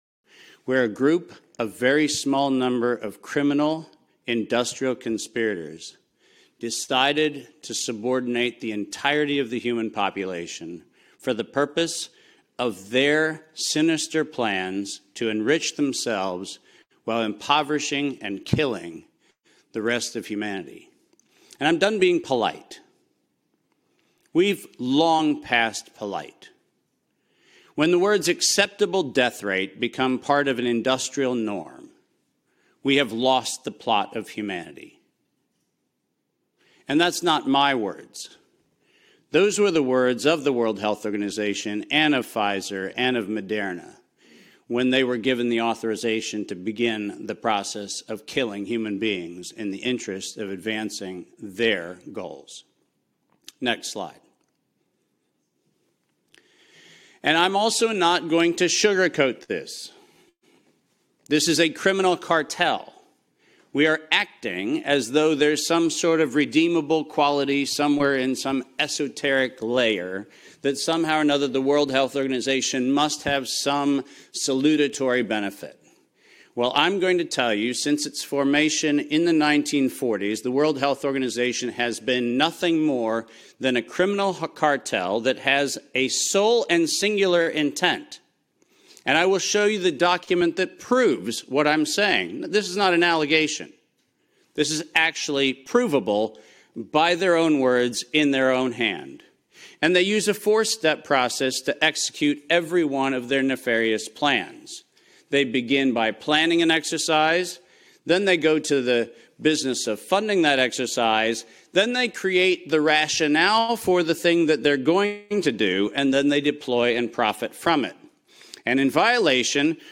2023 at the European Parliament in Strasbourg, France - exposing the WHO